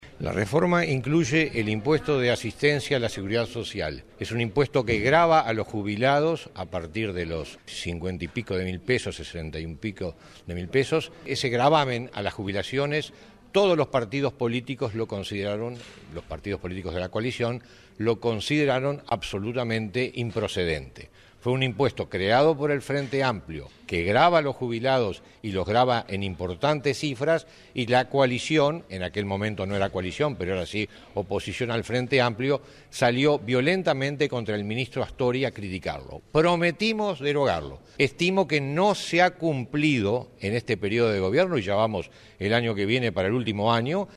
El diputado colorado Gustavo Zubía, anunció que no votará la reforma jubilatoria en el plenario porque no se deroga el IASS.